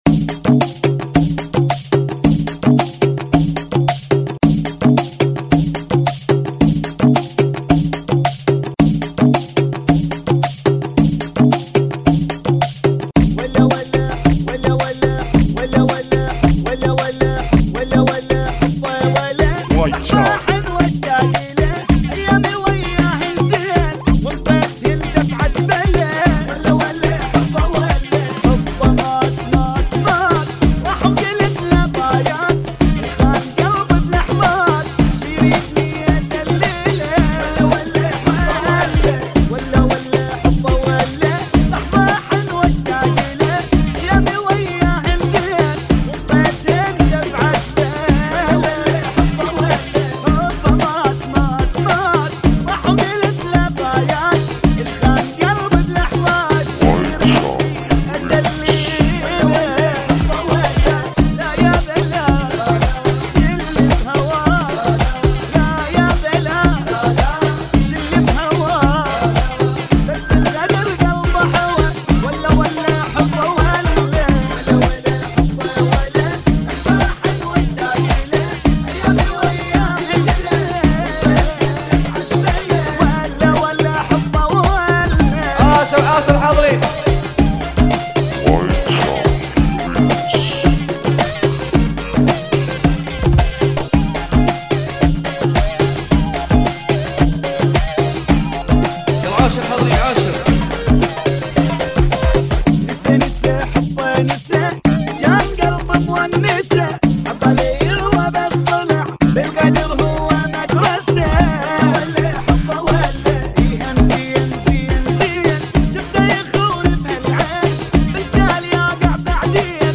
FUNKY MIX